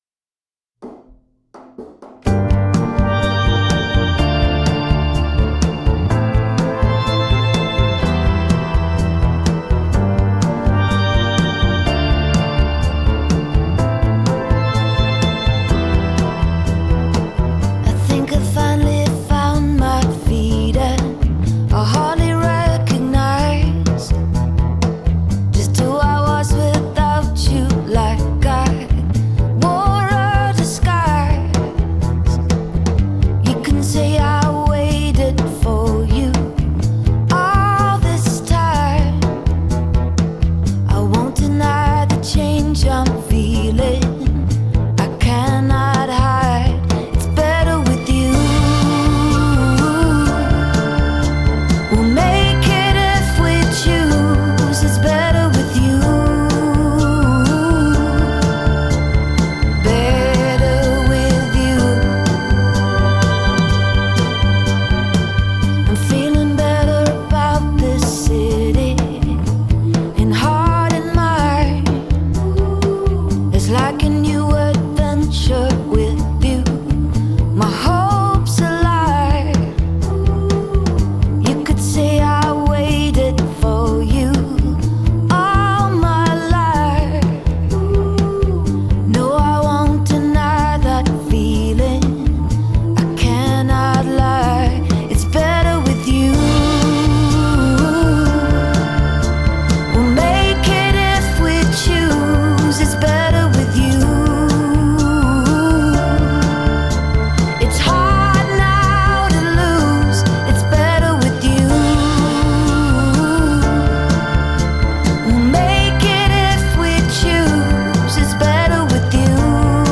Жанр: pop · female vocalists · indie · singer-songwriter